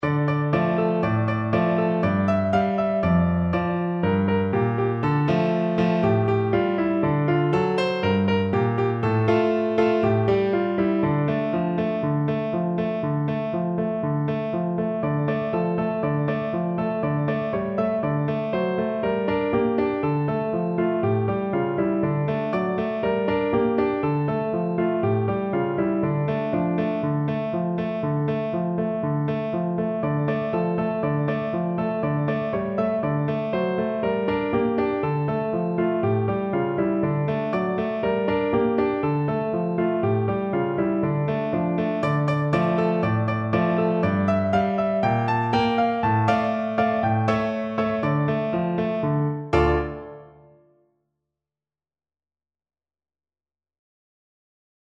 Piano version
No parts available for this pieces as it is for solo piano.
D minor (Sounding Pitch) (View more D minor Music for Piano )
Allegro (View more music marked Allegro)
2/4 (View more 2/4 Music)
Piano  (View more Easy Piano Music)
Classical (View more Classical Piano Music)
oi_marichko_PNO.mp3